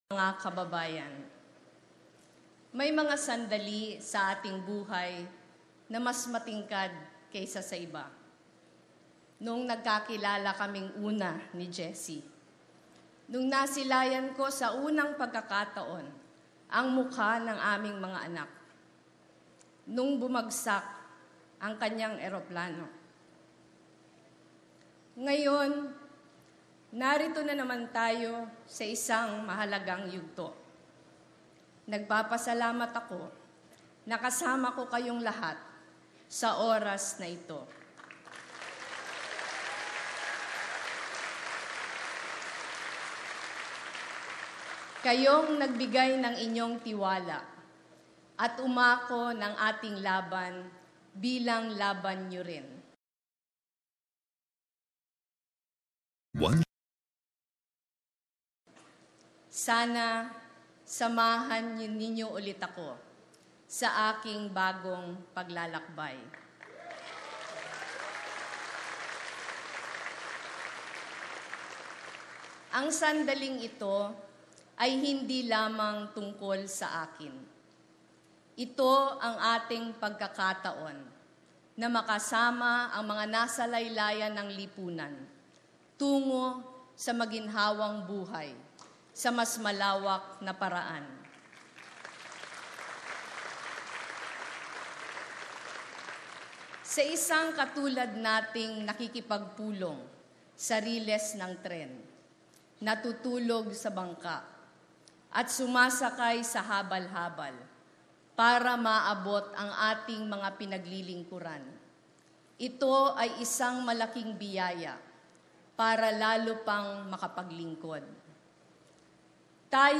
Inaugural speech of Vice President Leni Robredo
Here is the complete inaugural speech of Vice-President Leni Robredo, 16th Vice-President of the Republic of the Philippines, delivered in Quezon City, 30th June Image: VP Leni Robredo (Rappler)